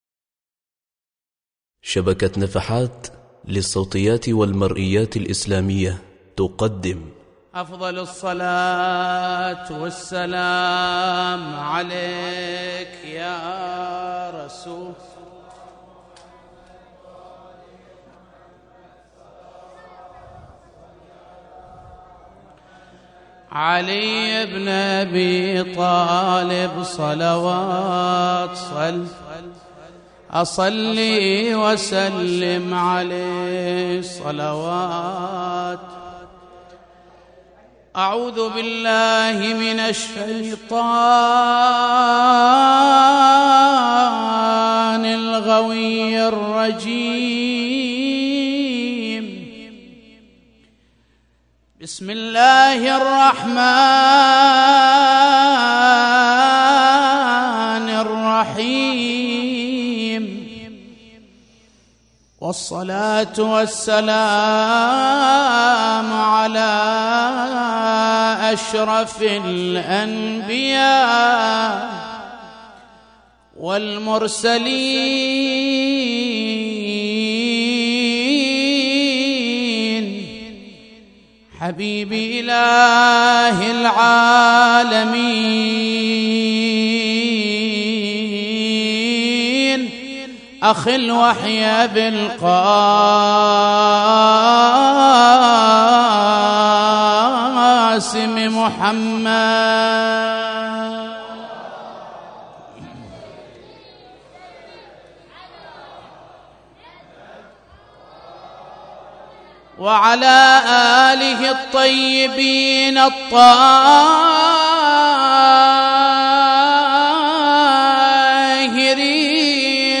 مولد الإمام زين العابدين ع -1435هـ – حسينية الأئمة ع بتاروت